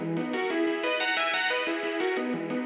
Index of /musicradar/shimmer-and-sparkle-samples/90bpm
SaS_Arp01_90-E.wav